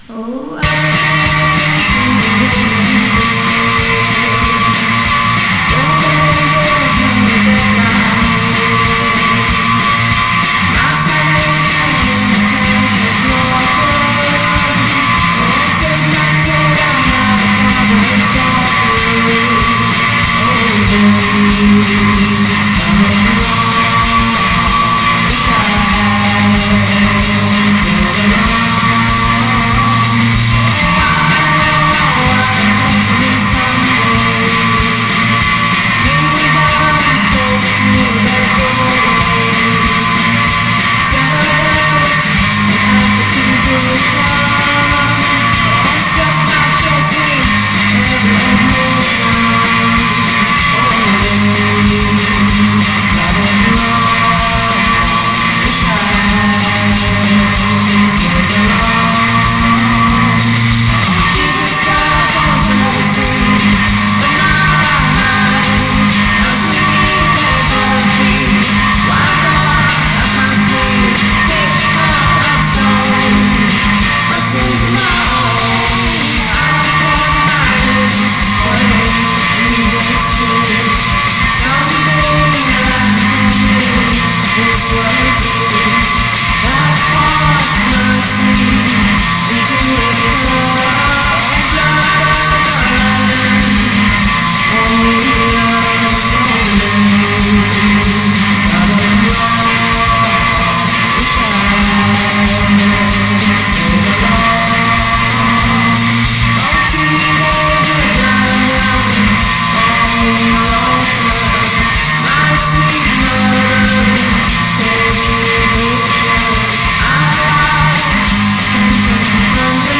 suona in tutto e per tutto come "sixties girl group"